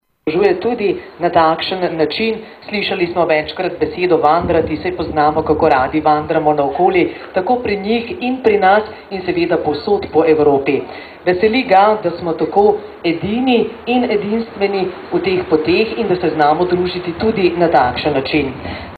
Pohod in srečanje ob 20 letnici evropske pešpoti E7 v Sloveniji